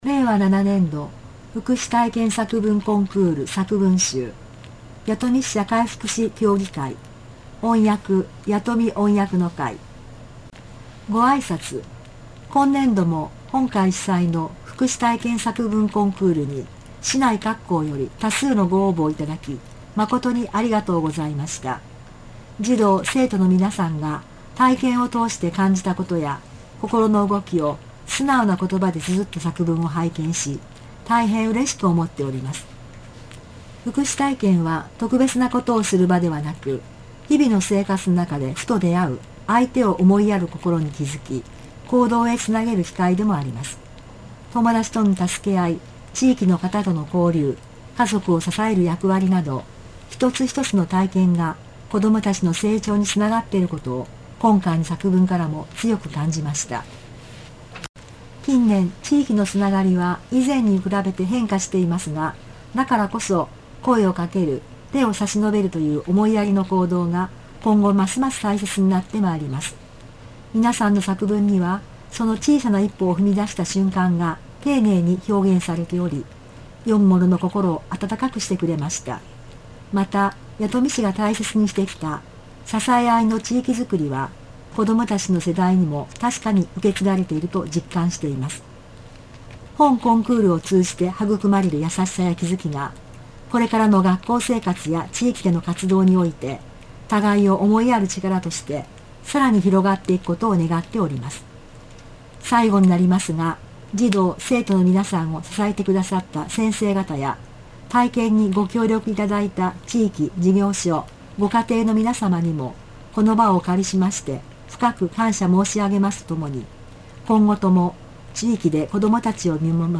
令和5年度 福祉体験作文コンクール 作文集 令和6年度 福祉体験作文コンクール 作文集 令和7年度 福祉体験作文コンクール 作文集 令和7年度 福祉作文コンクール 作文集 音声データ(36MB/39分27秒/弥富音訳の会) 歳末福祉映画会・講演会の開催 映画・講演会を通じて、市民の福祉への関心を高めるとともに、歳末たすけあい募金による相互のたすけあい意識の向上を図ることを目的に実施をしています。